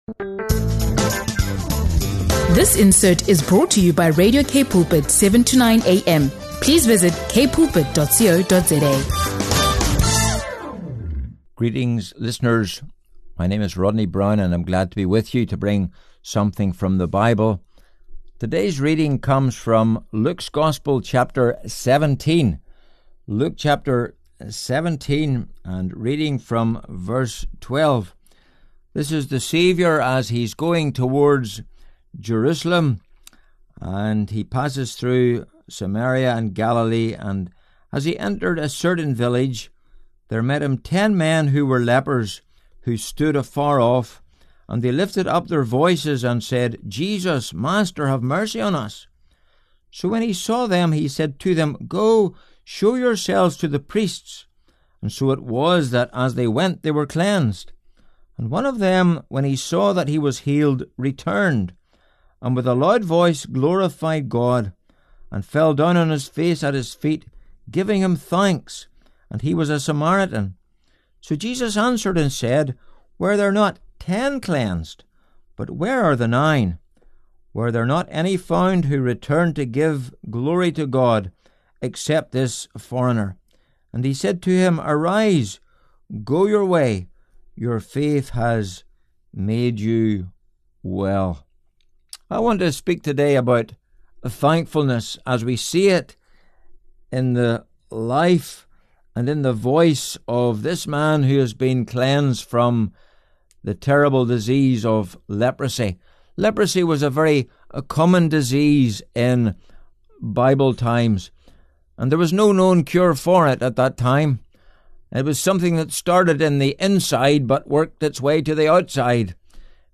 Luke 17 Bible Study